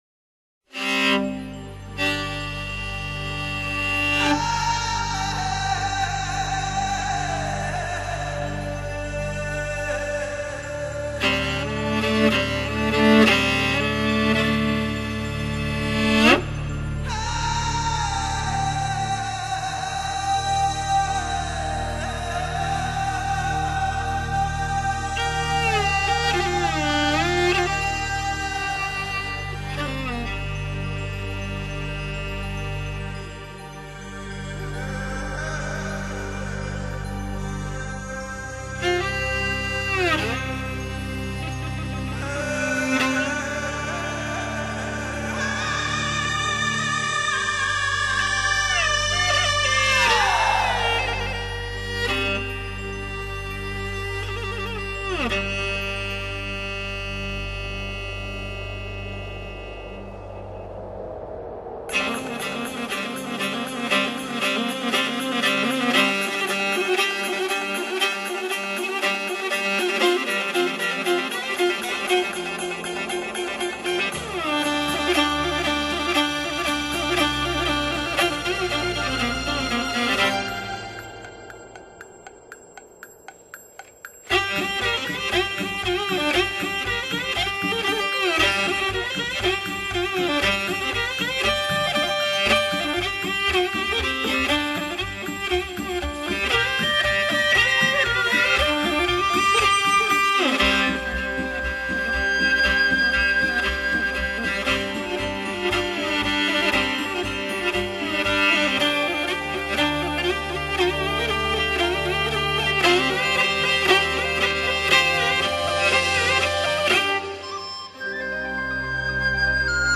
马头琴